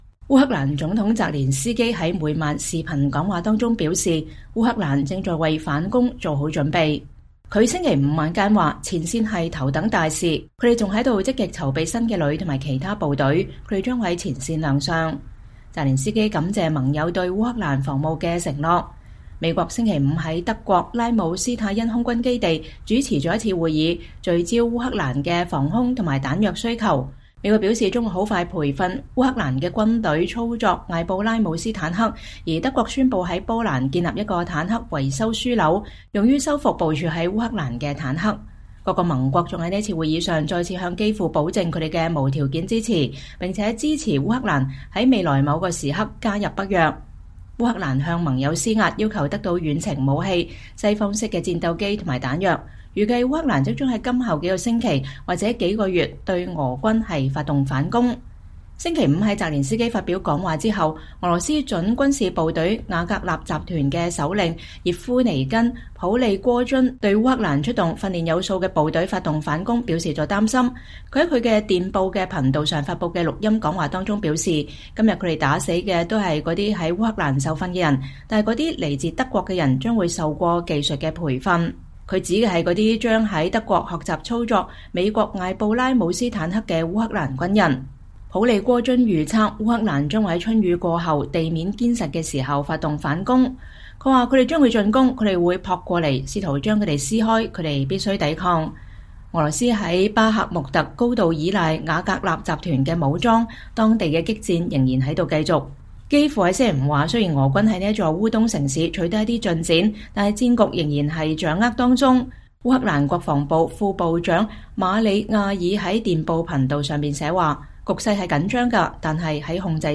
烏克蘭總統弗拉基米爾·澤連斯基(Volodymyr Zelenskyy)在每晚視頻講話中說，烏克蘭正在為反攻做好準備。